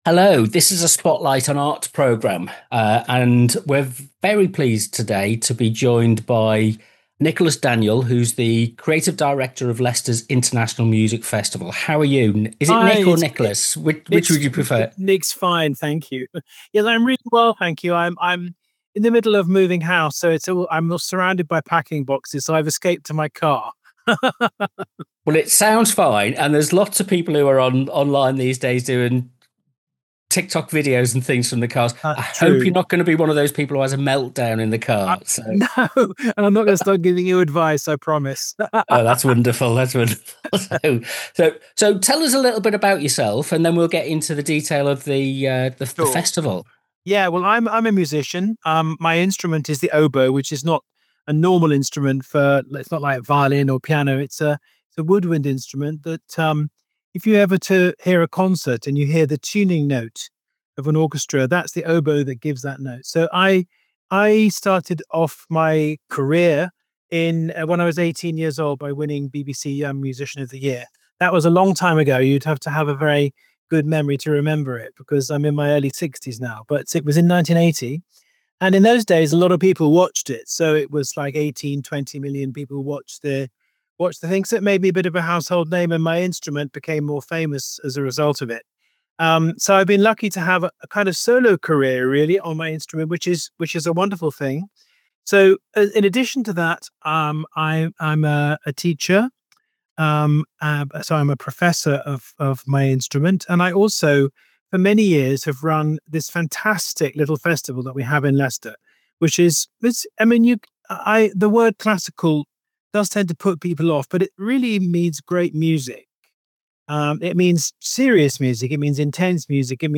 In this edition of Spotlight on Arts we talk with Nicholas Daniel OBE, one of Britain’s most celebrated oboists and the long-standing Creative Director of the Leicester International Music Festival.
Throughout the interview Nicholas reflects on why live music matters.